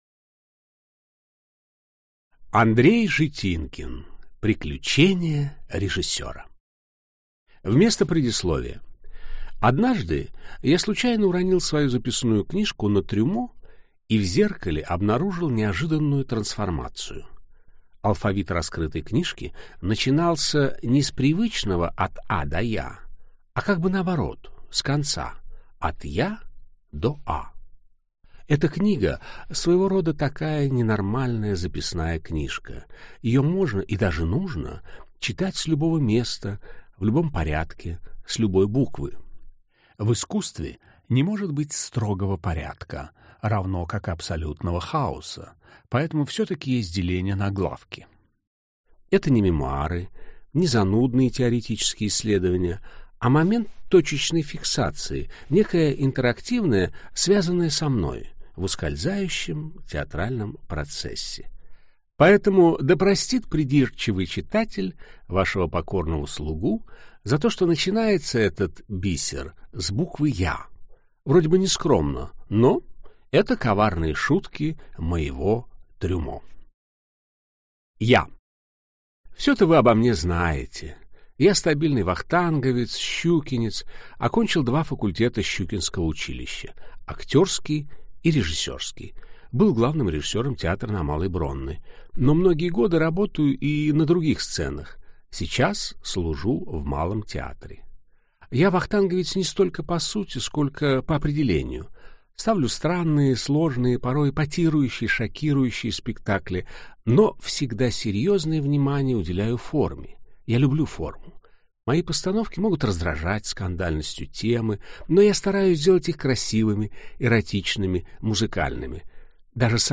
Аудиокнига Приключения режиссера | Библиотека аудиокниг